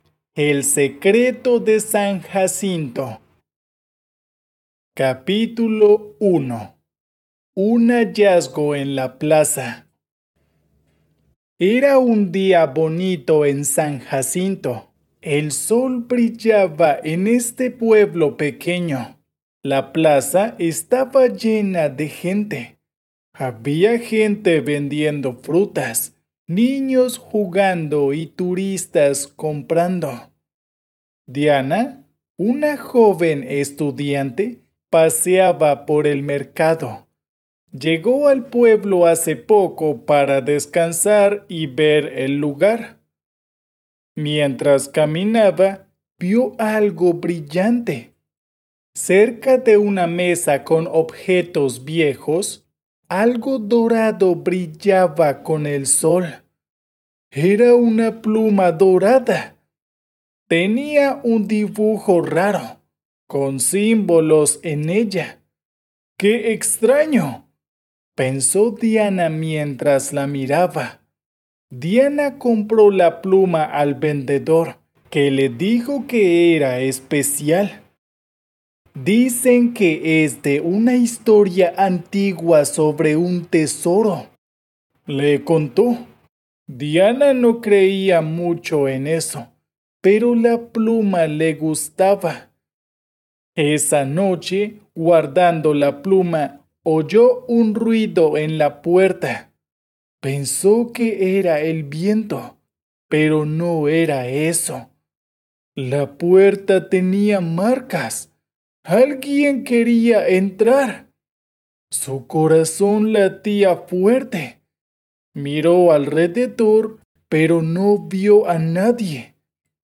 Spanish online reading and listening practice – level A2
audio by a professional Latin American voice actor